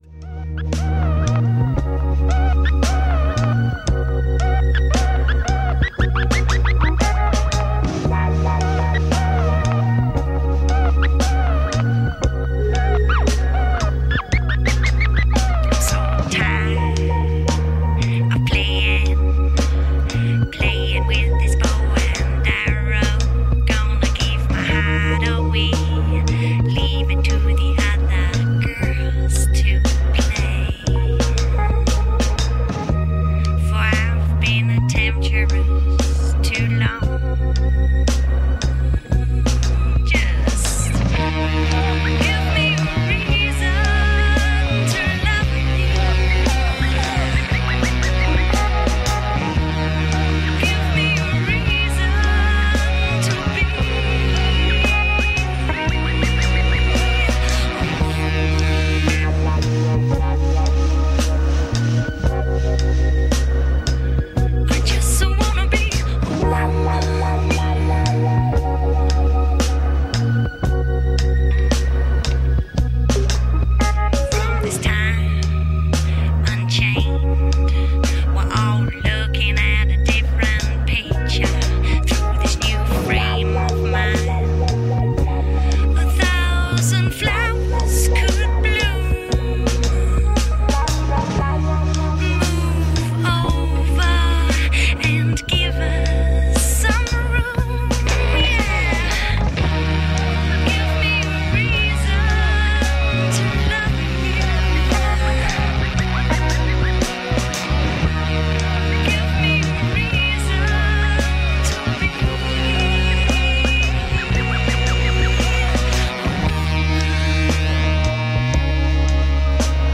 BBC Radio 1 session
Trip-Hop
searingly emotive voice
Pioneers of Trip Hop